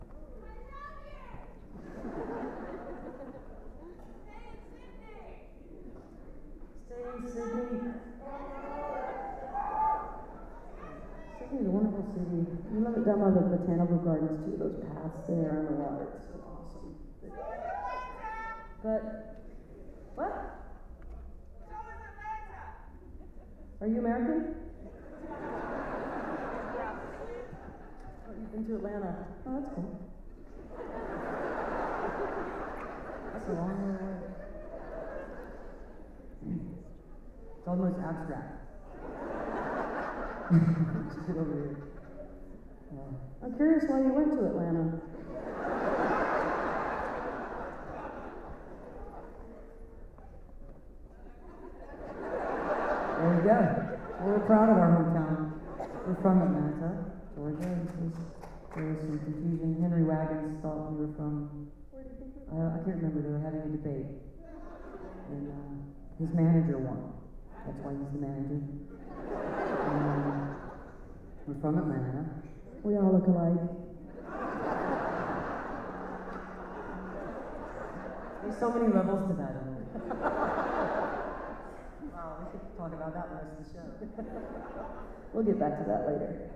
09. talking with the crowd (1:26)